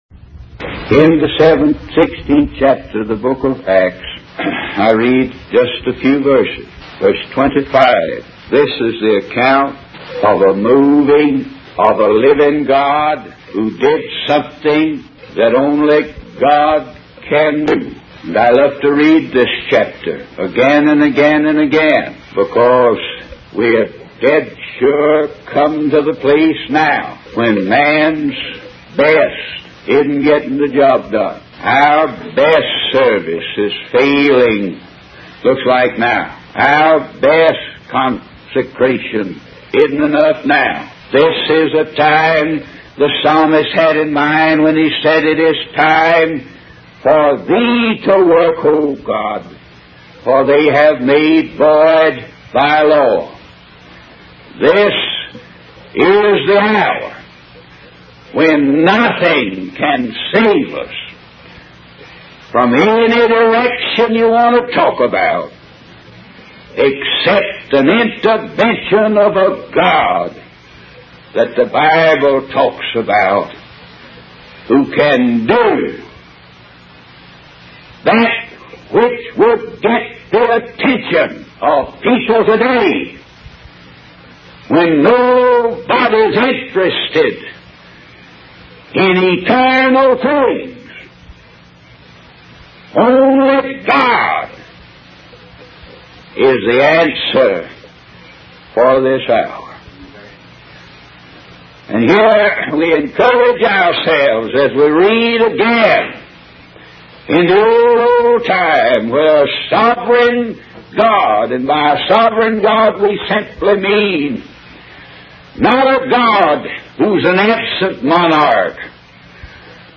In this sermon, the preacher focuses on the 7th and 16th chapters of the Book of Acts. He emphasizes the need for a revival in America and the importance of witnessing to others about the way of salvation.